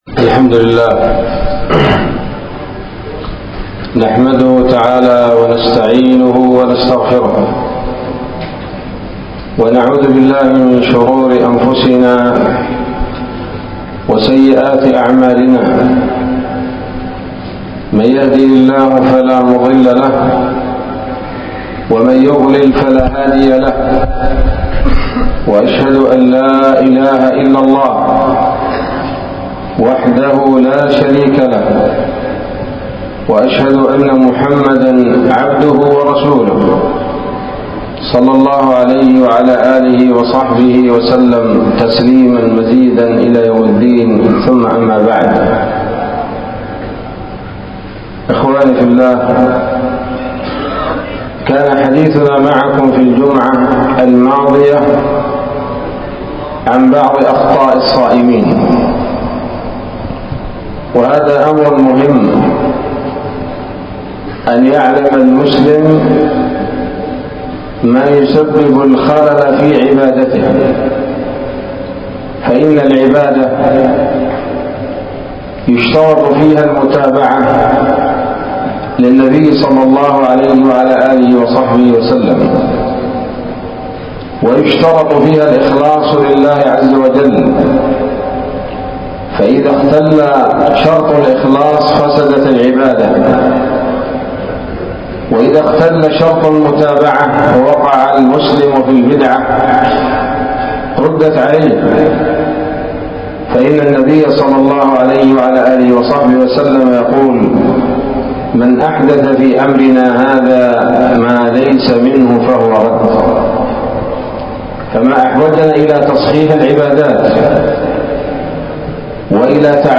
محاضرة بعنوان: (( بعض أخطاء الصائمين 2 )) عصر يوم الجمعة، 10 رمضان 1444 هـ، بجامع الكبير بصلاح الدين